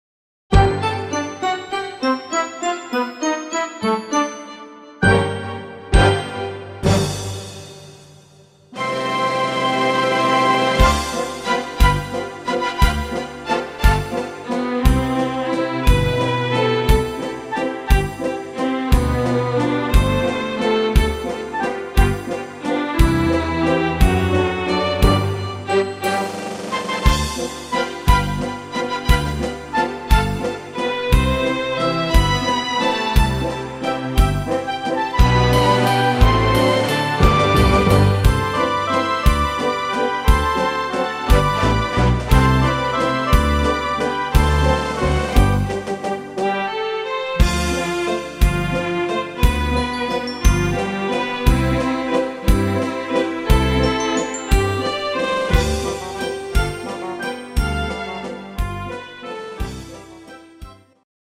Wiener Walzer